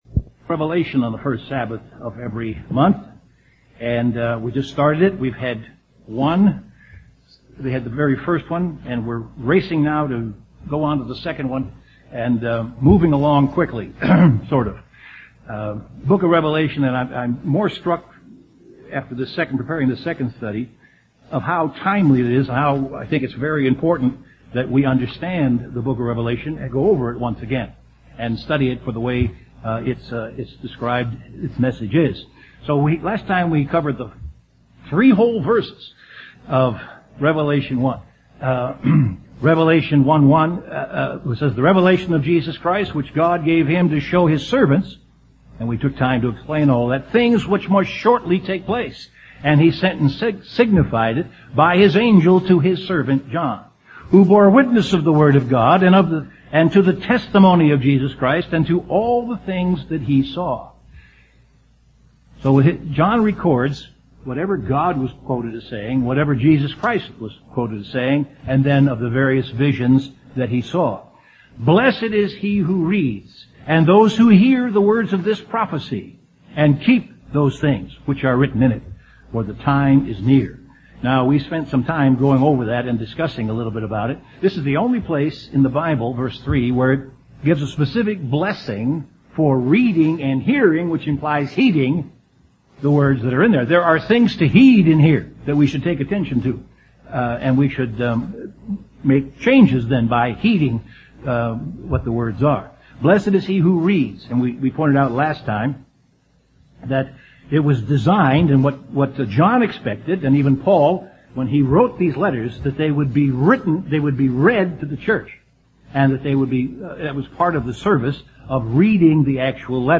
Given in Chicago, IL Beloit, WI
UCG Sermon